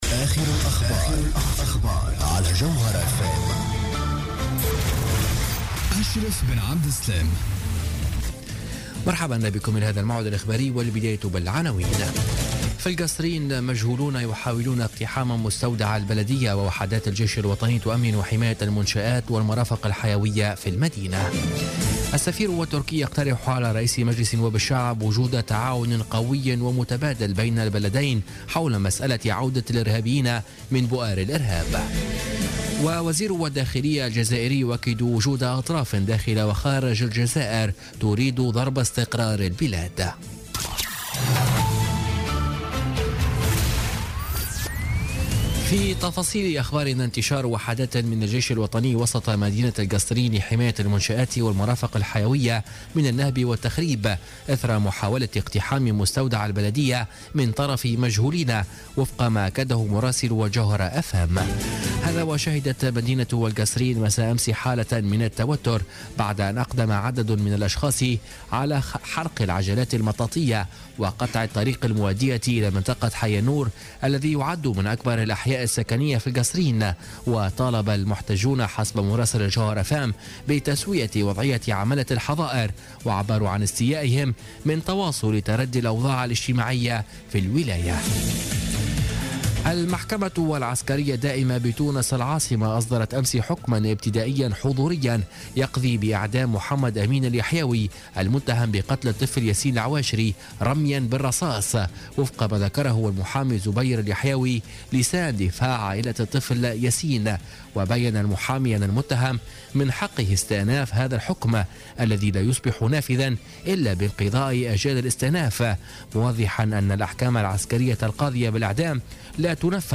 نشرة أخبار منتصف الليل ليوم الأربعاء 4 جانفي 2017